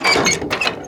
GearUp.wav